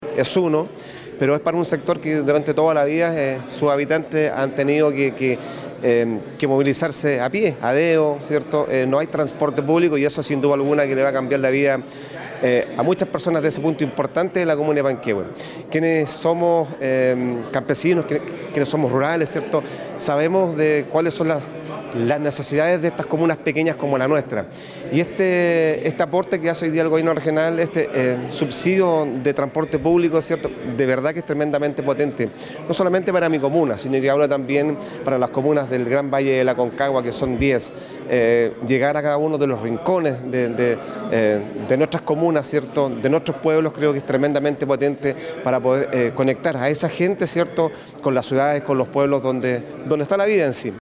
Por su parte, el Alcalde de Panquehue, Gonzalo Vergara, comentó cuantos nuevos recorridos sumará su comuna.
Alcalde-Panquehue.mp3